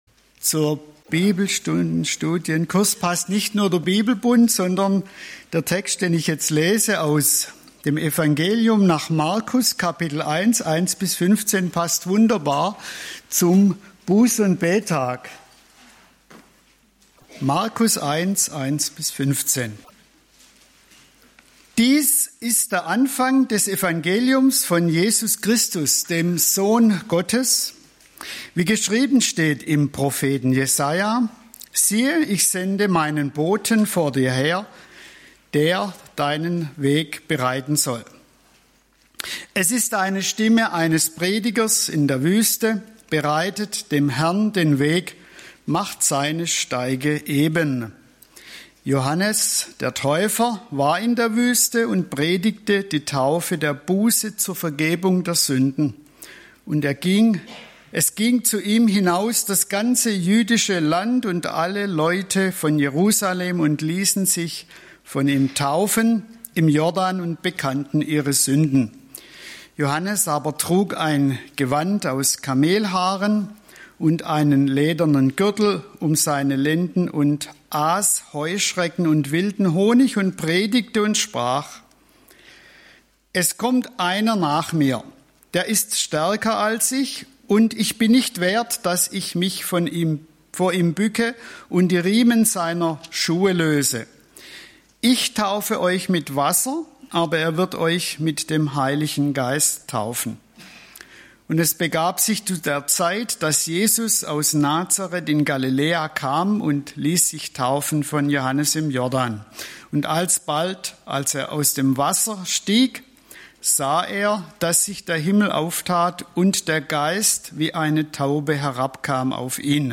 Gottesdienste und Bibelstunden der Langensteinbacher Höhe